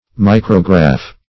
micrograph - definition of micrograph - synonyms, pronunciation, spelling from Free Dictionary
Micrograph \Mi"cro*graph\, n. [See Micrography.]